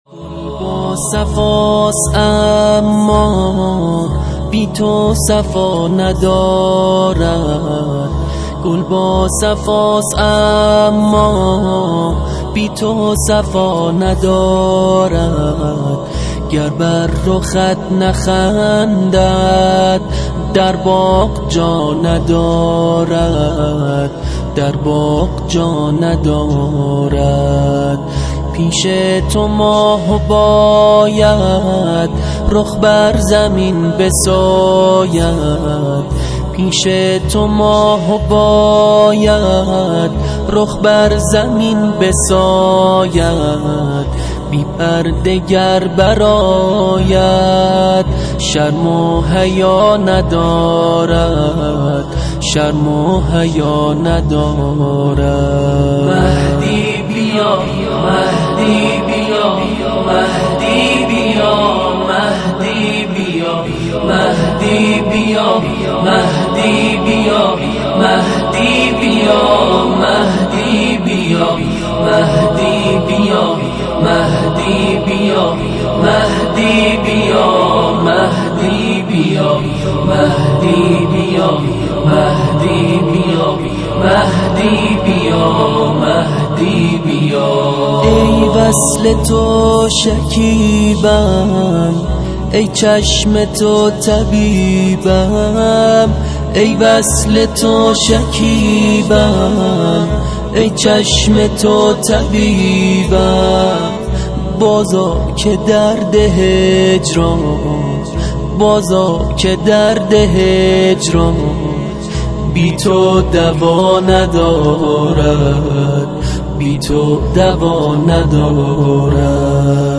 تواشیع2